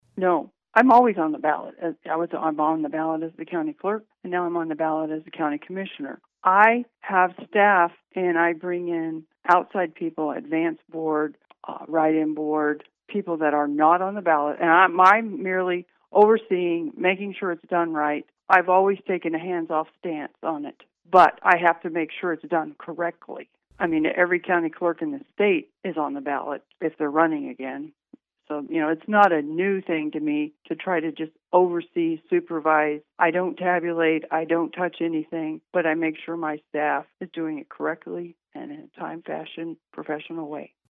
KMAN spoke with McCarter about what would make her a good county leader and her stances on various county issues.